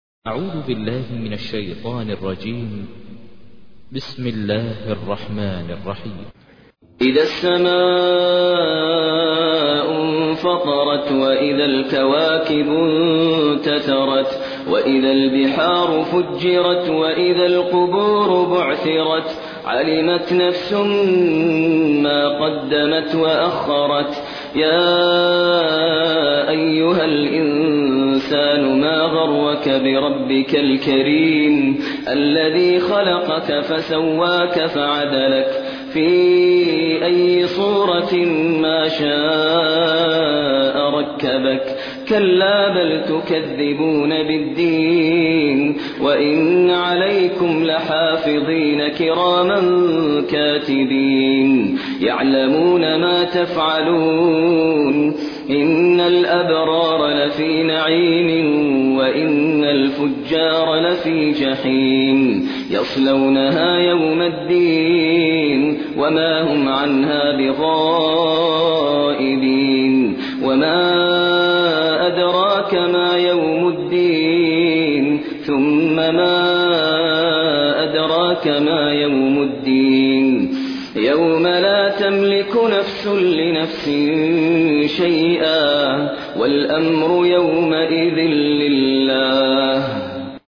تحميل : 82. سورة الانفطار / القارئ ماهر المعيقلي / القرآن الكريم / موقع يا حسين